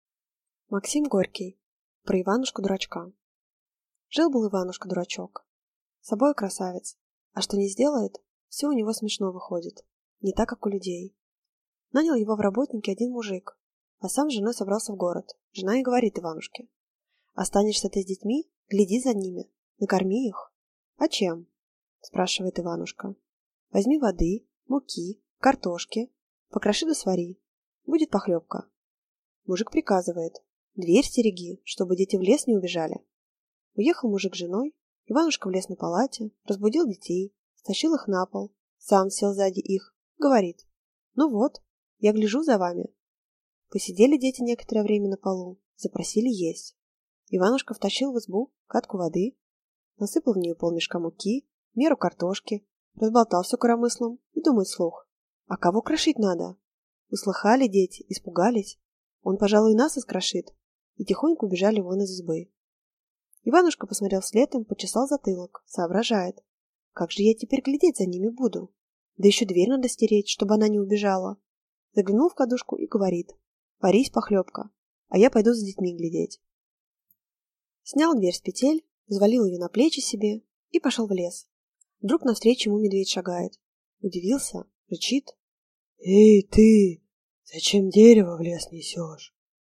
Аудиокнига Про Иванушку-дурачка | Библиотека аудиокниг